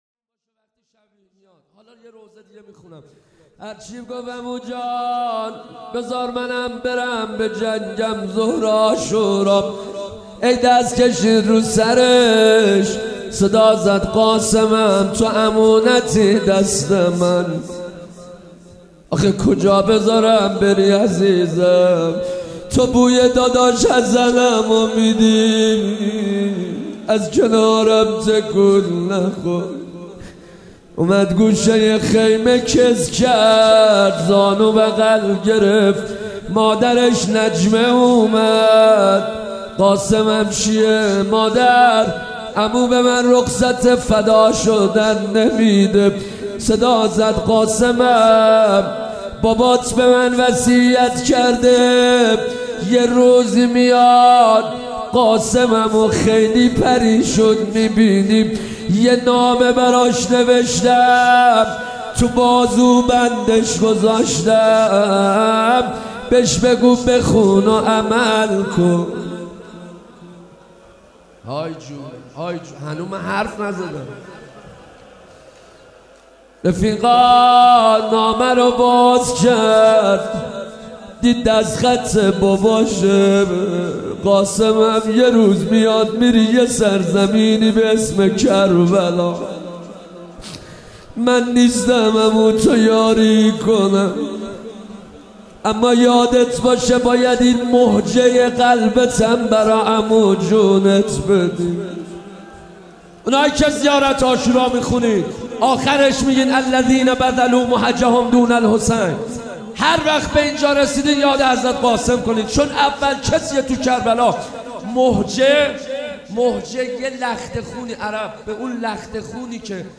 روضه حضرت قاسم